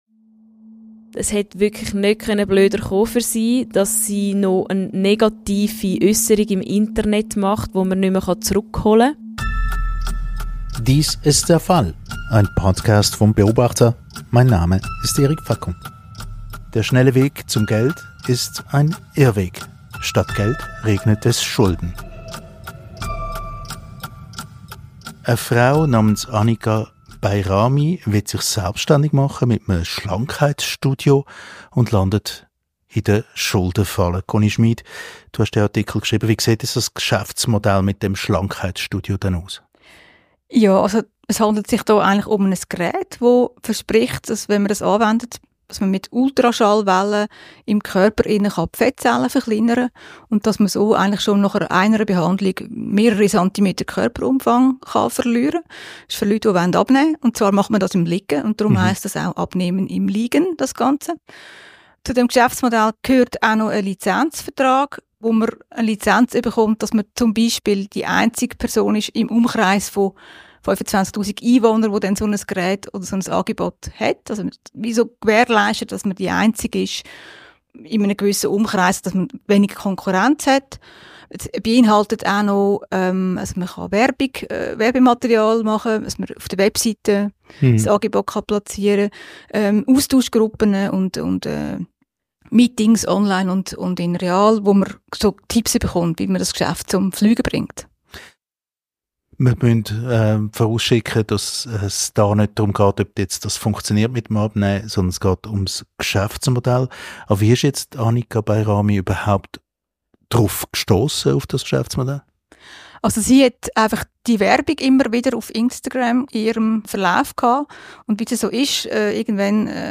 Journalistinnen und Journalisten schildern im Gespräch